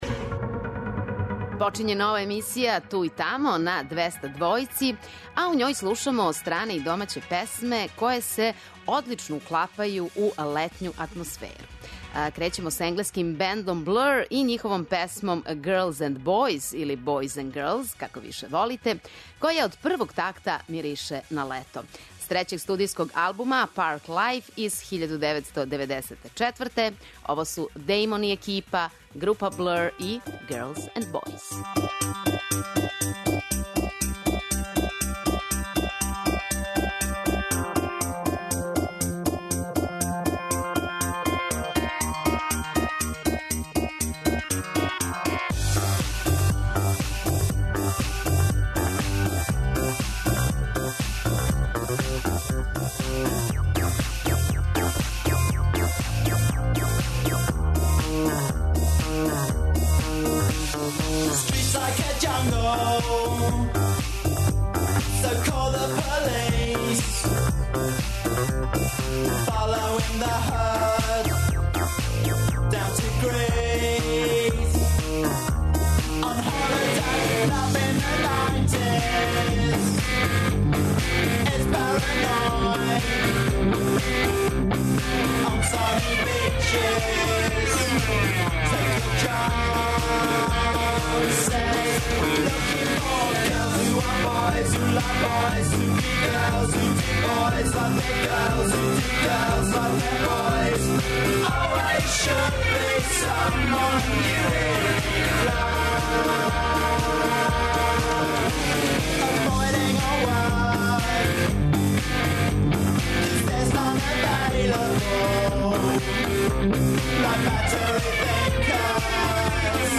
Нова емисија Ту и тамо доноси посебан избор хитова у којима се осећа летња атмосфера.